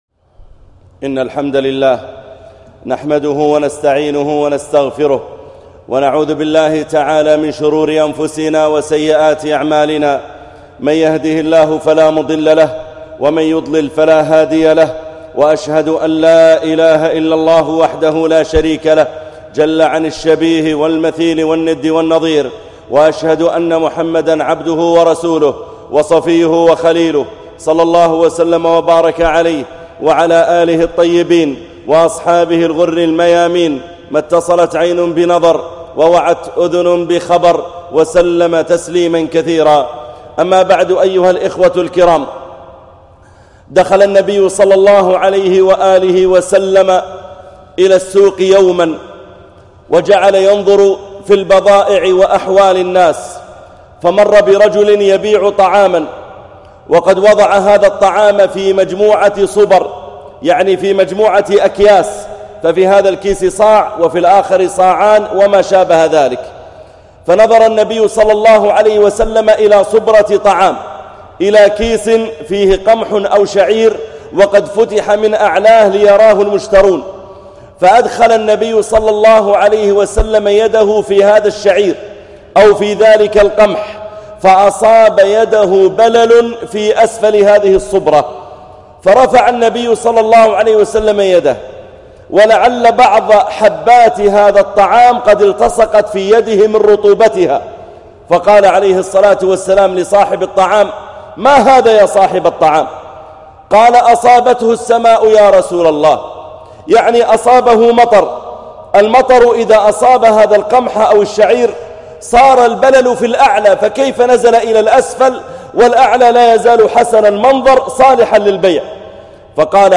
رد المظالم _ خطبة الجمعة - الشيخ محمد العريفي